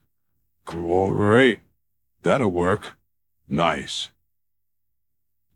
bear-a.wav